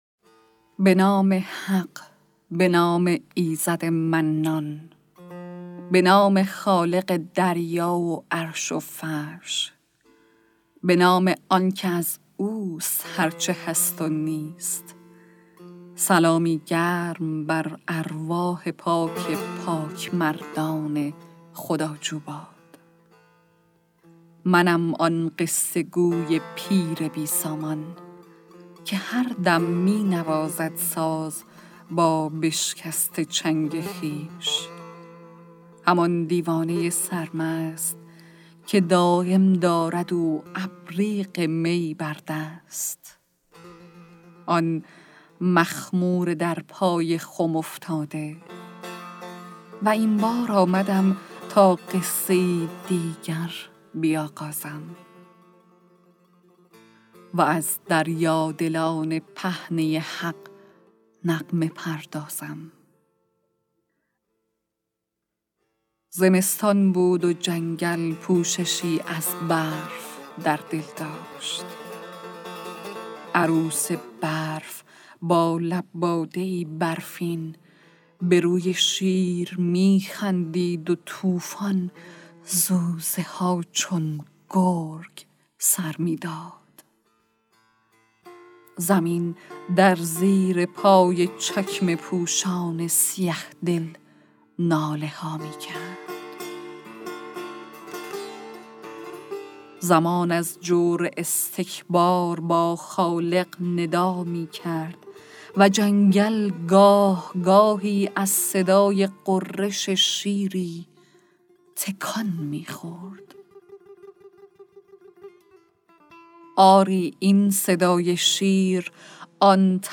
«شمال حماسه» کتاب صوتی اشعار شاعران شهید استان گیلان